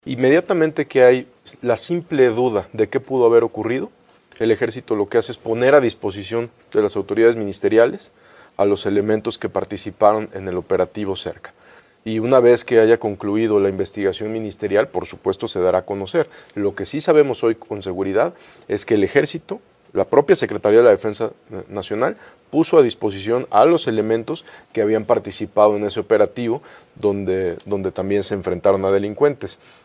Omar García Harfuch, actual funcionario de seguridad federal, confirmó en un programa informativo de cadena nacional-Ciro Gómez Leyva- que los primeros indicios señalan que las balas que acabaron con la vida de las niñas provenían de armas de uso militar.